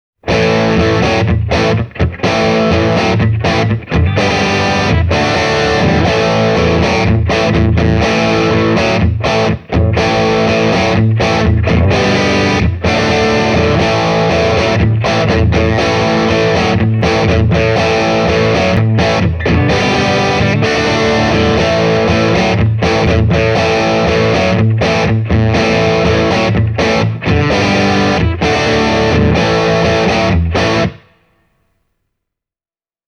Channel 1 will take you from clean all the way to Rockbilly-style breakup and traditional Blues overdrive, while Channel 2 offers more than enough dirt for chunky Rock tones.
…and here are two clips of Channel 2 with Gain full up: